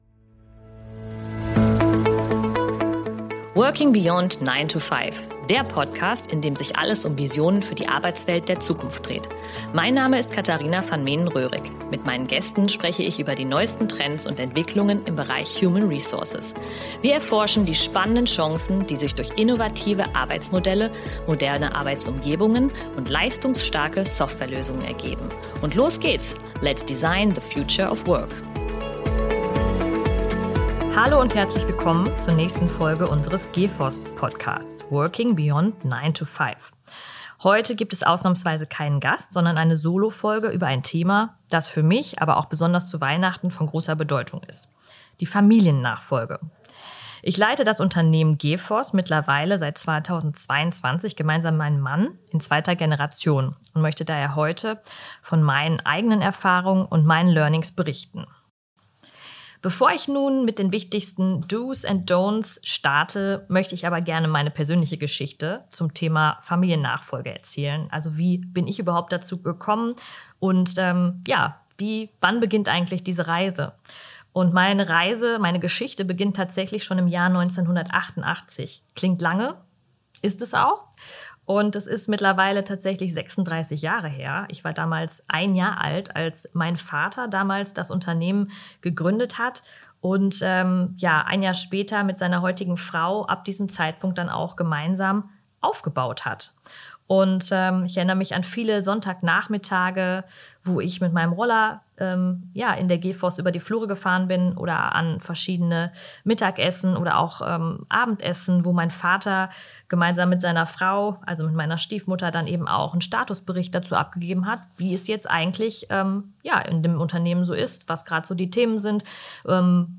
Darum berichtet sie diesmal solo über ihre Erfahrungen und erläutert die wichtigsten DOs and DON’Ts.